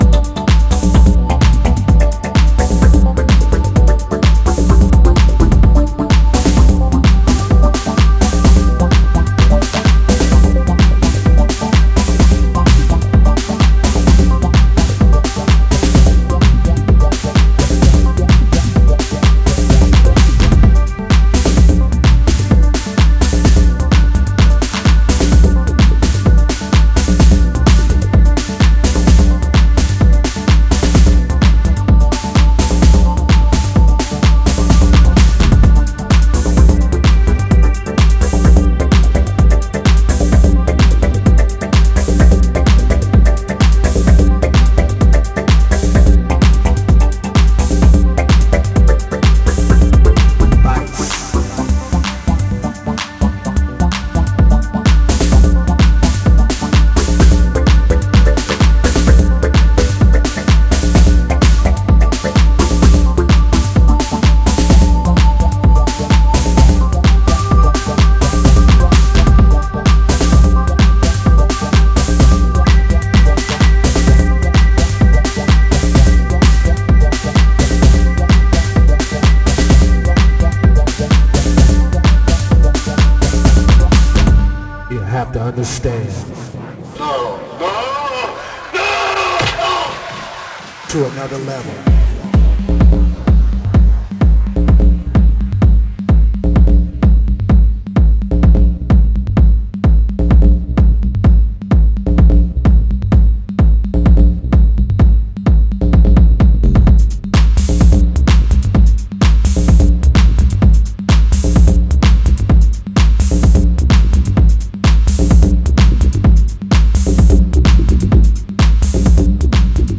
図太いうねりを帯びたボトムとヒプノティックなリフを軸に引き込んでいくウェストコースト・ハウスに仕上がっていて最高です。